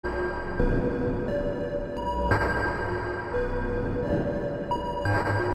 描述：诡异的环境循环
Tag: 175 bpm Ambient Loops Pad Loops 948.86 KB wav Key : Unknown